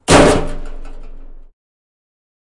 废弃的工厂金属后世界末日的回声" 爆炸4
描述：记录在都柏林的一家废弃工厂。
Tag: 工业 工厂 金属 崩溃 噪声